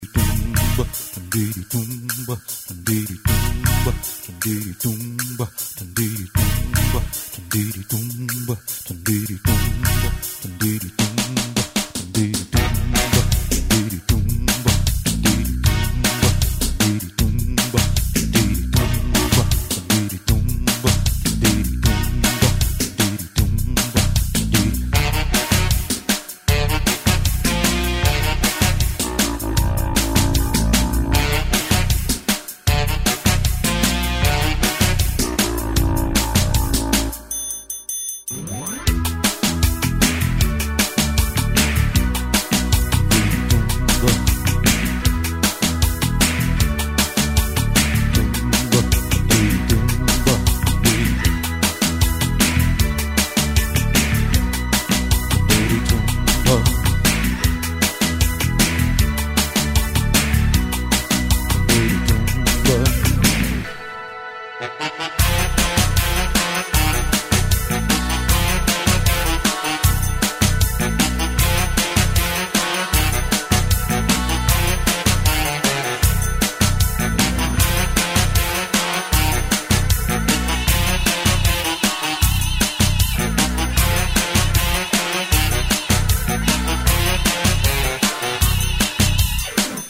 смешные
забавная мелодия из юмористического шоу